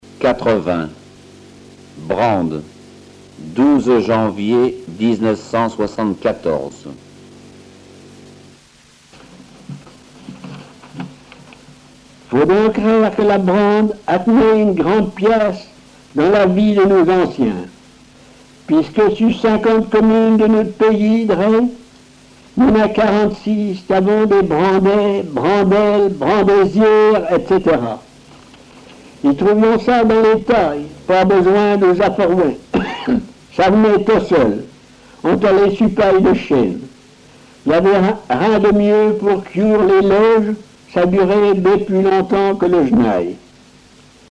Genre récit
Récits en patois
Catégorie Récit